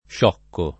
scioccare v.; sciocco [ + š 0 kko ], sciocchi